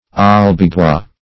Albigenses \Al`bi*gen"ses\, Albigeois \Al`bi`geois"\, n. pl.